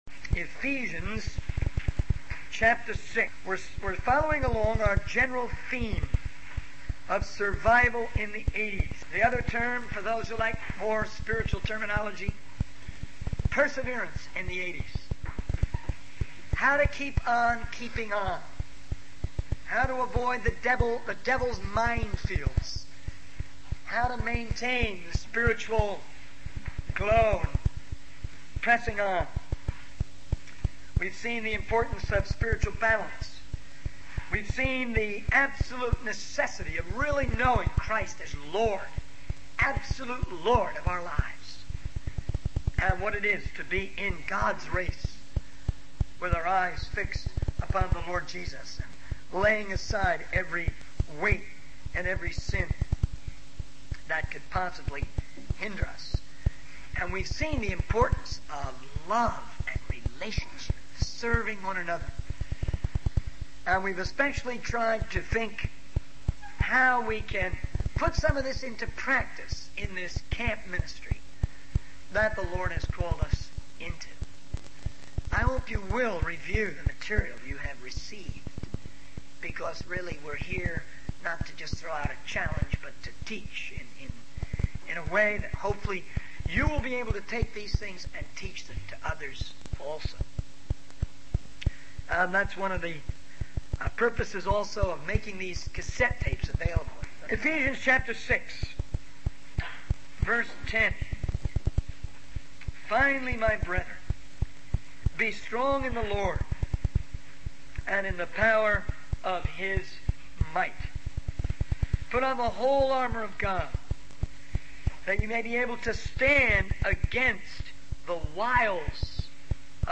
In this sermon, the speaker emphasizes the importance of love and relationship in serving one another.